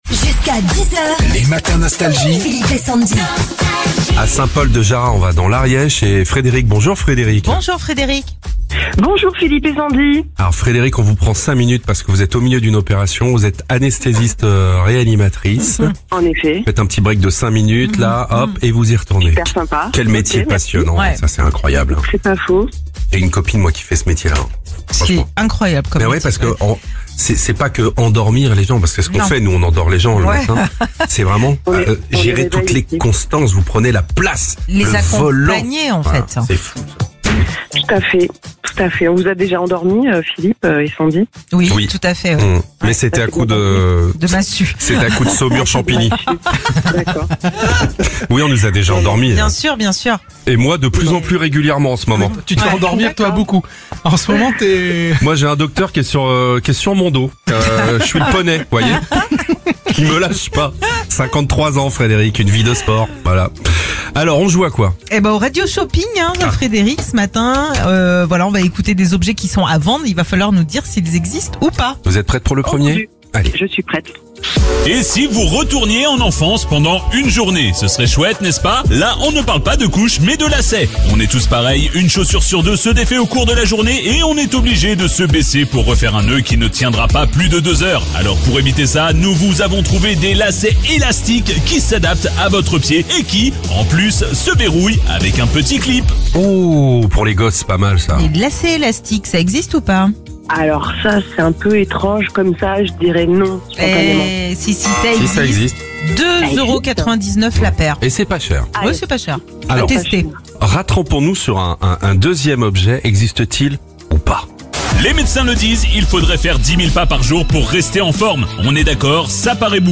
On joue au radio shopping ! La règle vous la connaissez, on écoute des objets qui sont à vendre, à vous de nous dire s’ils existent ou pas !